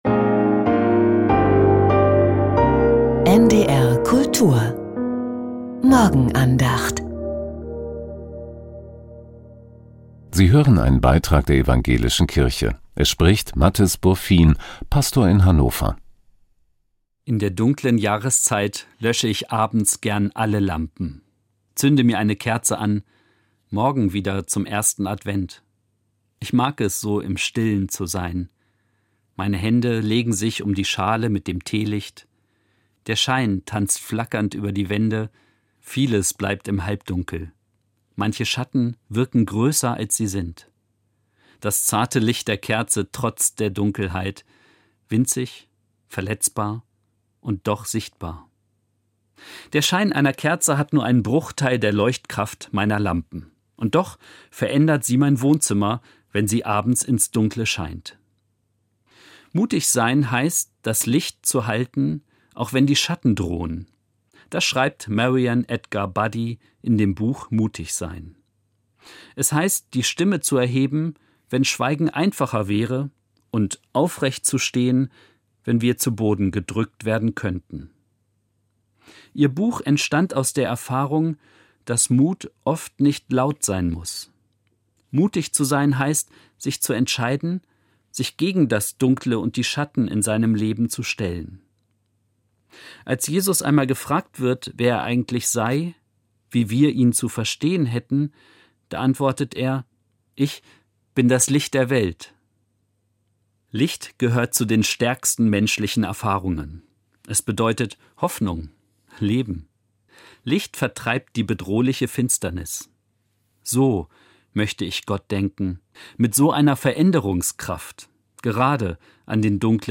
Vom Mut, zu vertrauen ~ Die Morgenandacht bei NDR Kultur Podcast